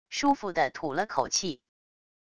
舒服的吐了口气wav音频